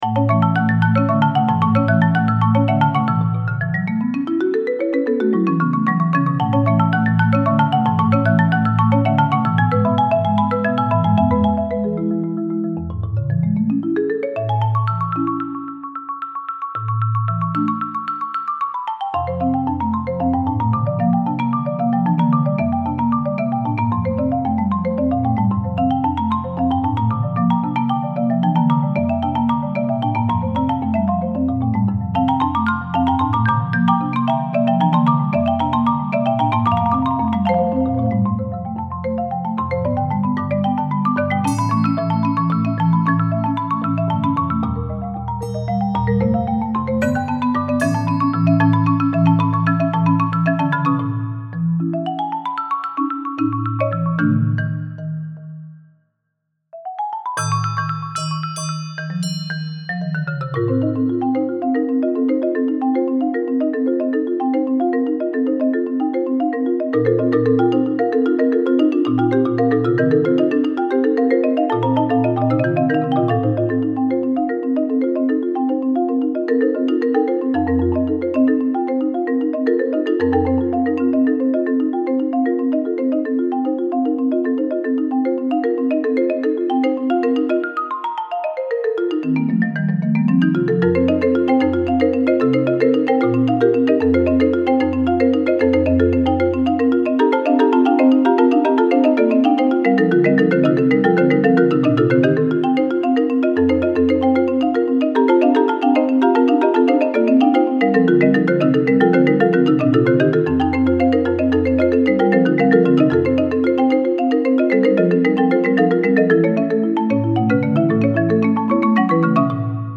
Genre: Marimba Quartet with Crotales
Players 1 & 2: Shared 5-octave Marimba